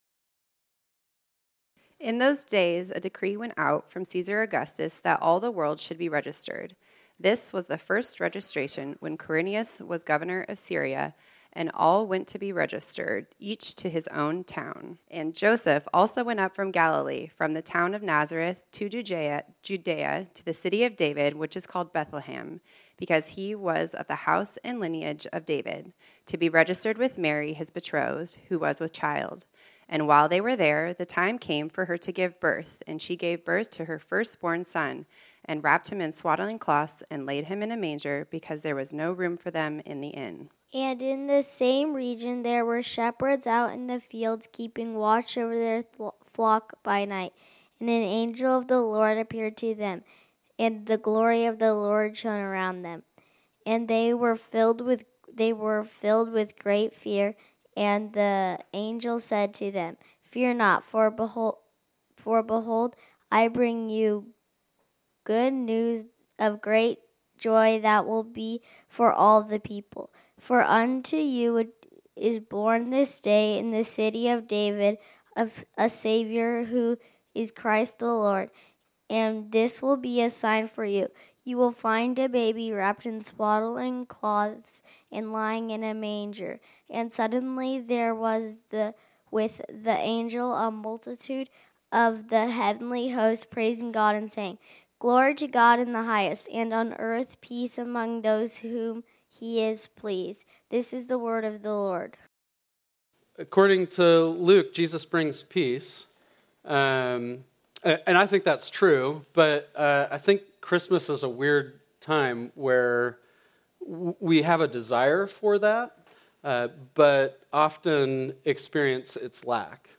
This sermon was originally preached on Sunday, December 15, 2024.